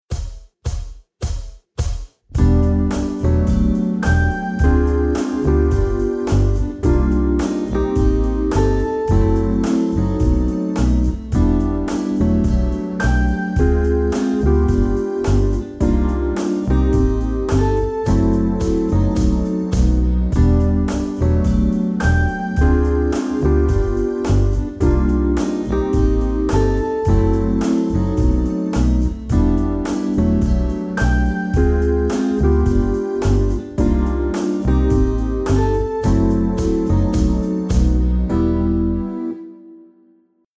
Akkordprogression med modulation og gehørsimprovisation:
Lyt efter bassen, der ofte spiller grundtonen.
Modulation: En stor terts op eller en stor terts ned
C instrument (demo)